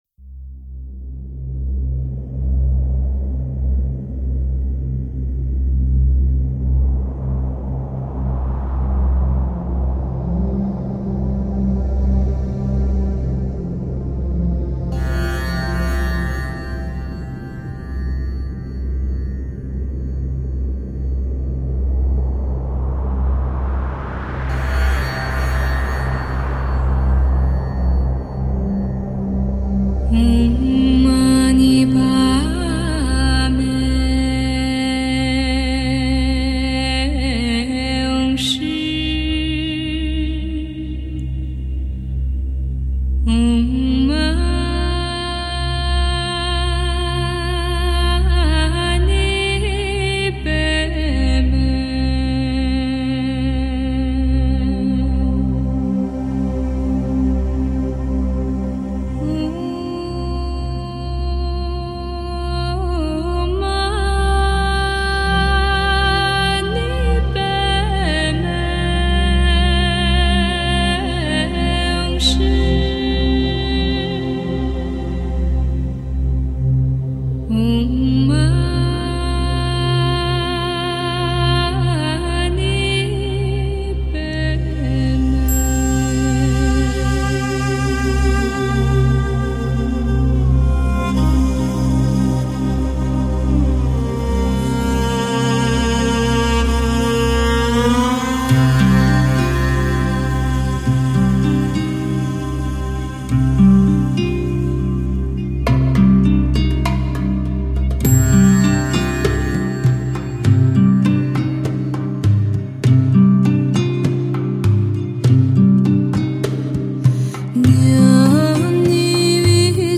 马头琴
吉　他
钢　琴
大提琴
手风琴
口　琴
打击乐
用最温柔安静的方式感动你
来自草原的寂静天籟